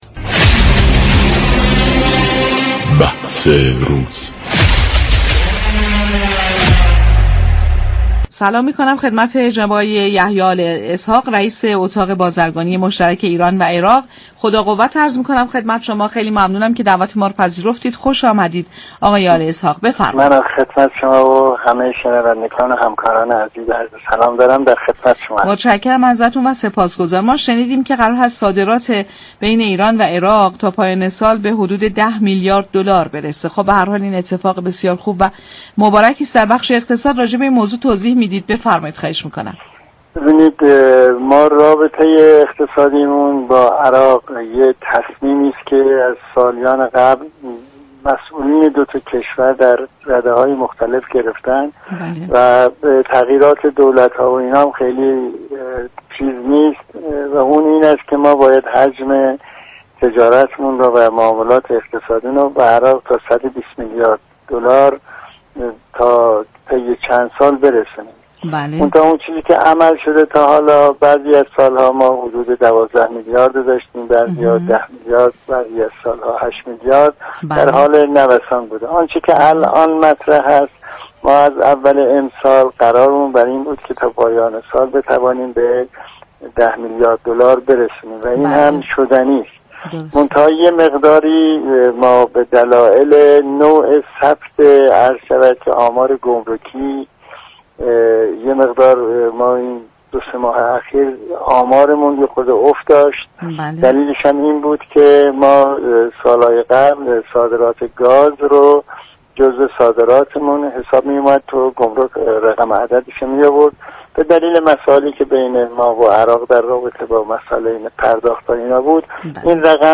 به گزارش پایگاه اطلاع رسانی رادیو تهران؛ یحیی آل اسحاق رئیس اتاق رئیس اتاق مشترك بازرگانی ایران و عراق در گفت و گو با "بازار تهران" رادیو تهران گفت: حجم معاملات اقتصادی بین ایران و عراق طی چند سال باید به 120 میلیارد دلار برسد.